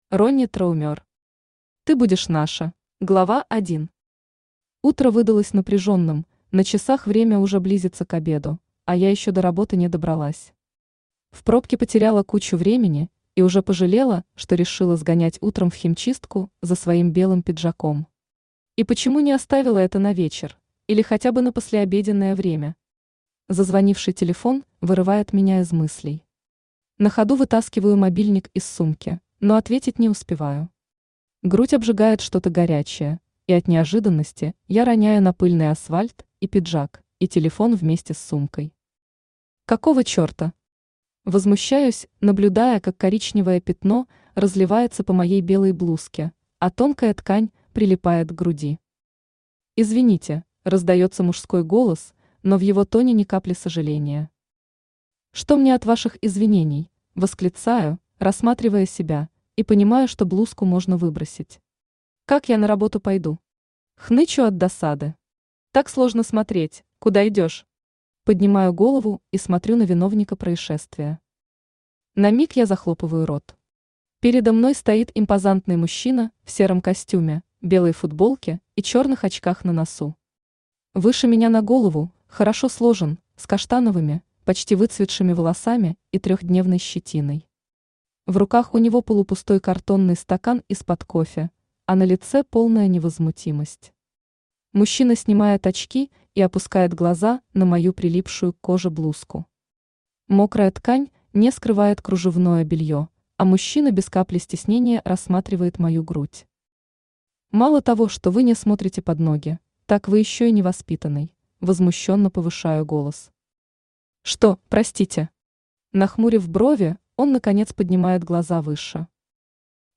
Aудиокнига Ты будешь наша Автор Ронни Траумер Читает аудиокнигу Авточтец ЛитРес.